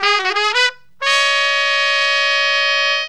HORN RIFF 21.wav